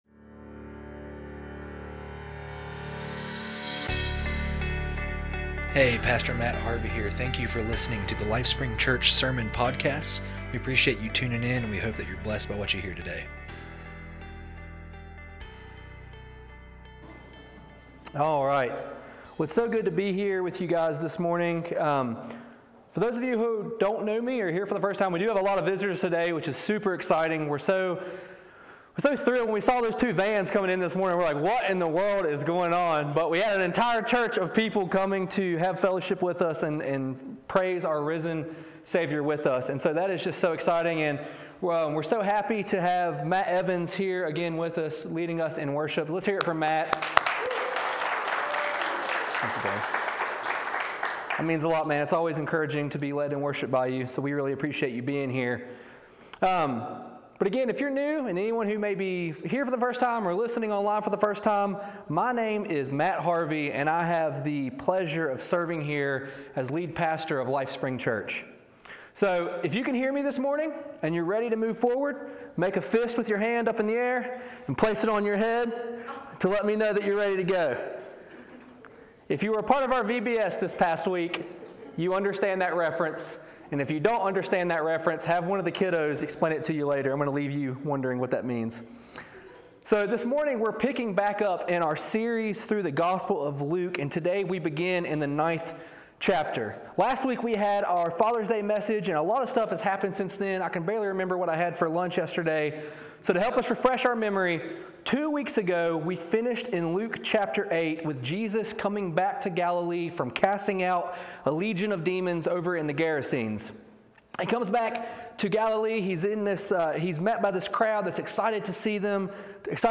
Sermons | LifeSpring Church